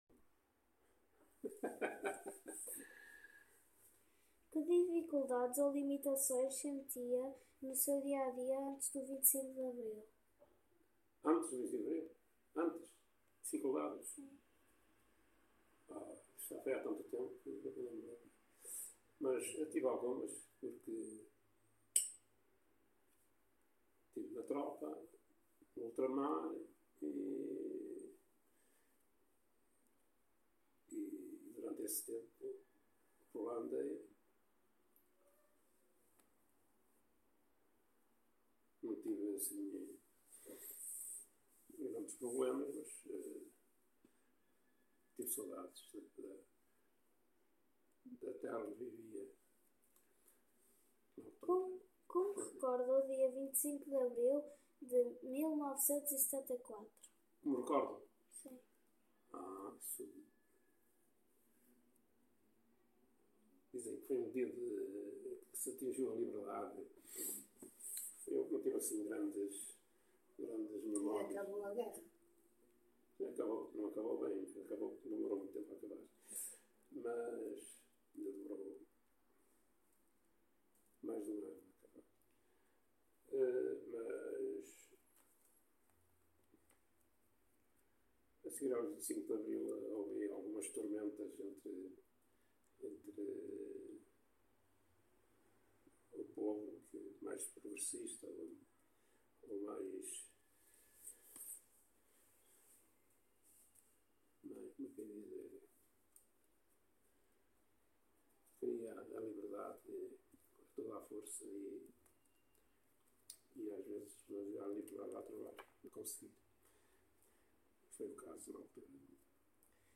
ENTREVISTA POR: